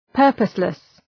Προφορά
{‘pɜ:rpəslıs} (Επίθετο) ● άσκοπος